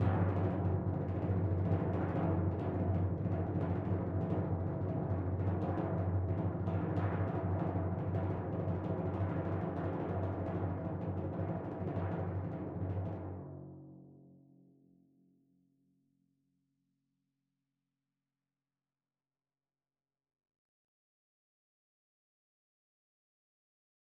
Timpani1_Roll_v5_rr1_Sum.wav